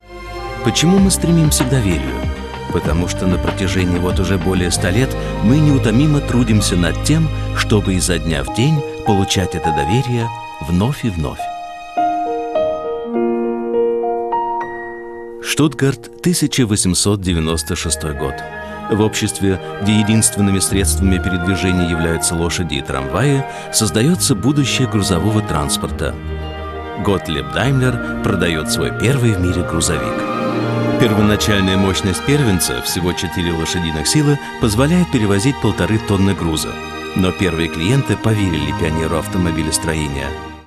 Sprecher russisch. Nativ Speaker. Stimmcharakter: werblich, freundlich, edel, dunkle Stimme,
Sprechprobe: Sonstiges (Muttersprache):